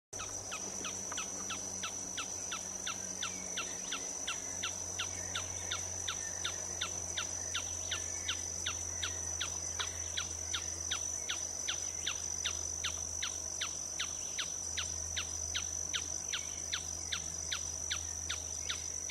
Tapaculo-ferreirinho (Scytalopus pachecoi)
Nome em Inglês: Planalto Tapaculo
Localidade ou área protegida: Parque Provincial Urugua-í
Condição: Selvagem
Certeza: Gravado Vocal